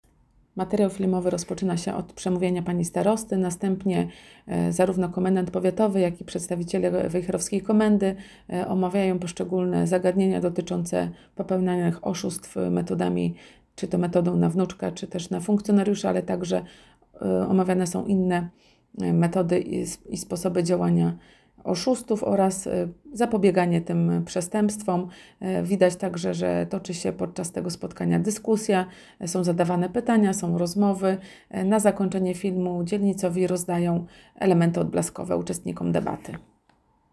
Debata społeczna „Porozmawiajmy o bezpieczeństwie- możesz mieć na nie wpływ” z seniorami na temat działania oszustów
Wczoraj Starostwie Powiatowym w Wejherowie odbyła się debata społeczna inauguracyjna poświęcona bezpieczeństwu osób starszych.
Podczas spotkania policjanci informowali o różnych zagrożeniach, z którymi mogą się spotkać niemal każdego dnia seniorzy.